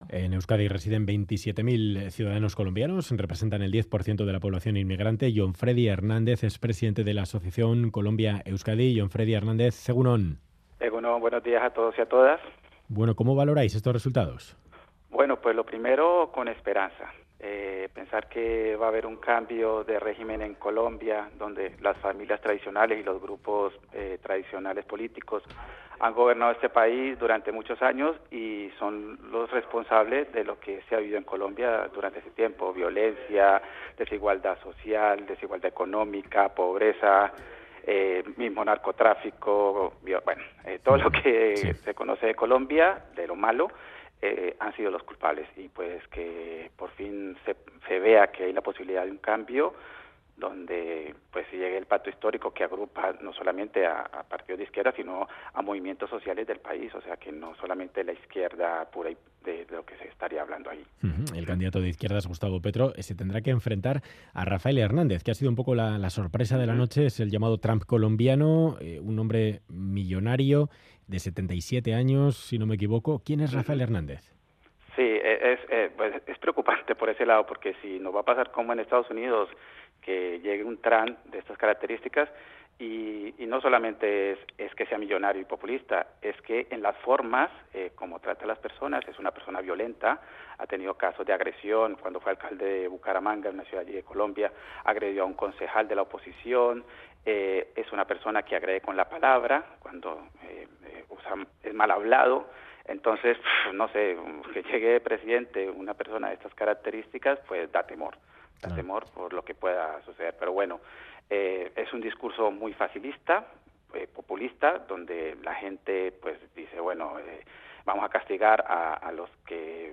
Radio Euskadi ENTREVISTA